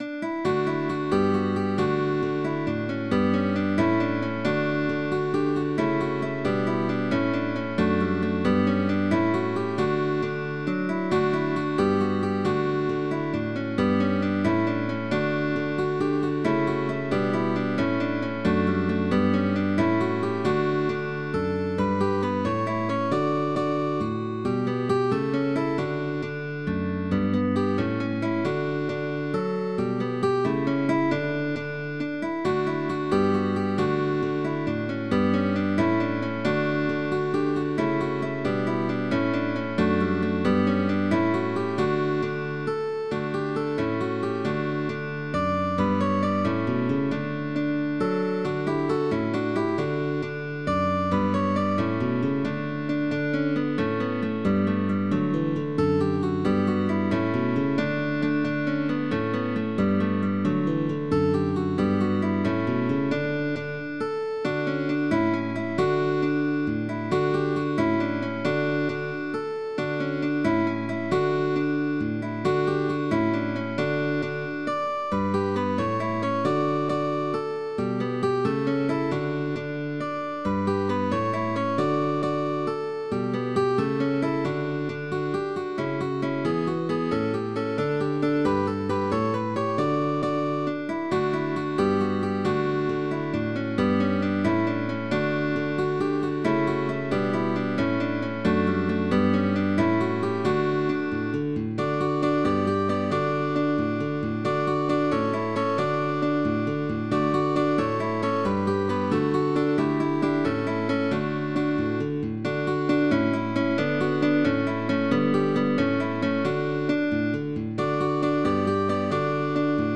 guitar trio
GUITAR TRIO
Arpeggios for three fingers.
Baroque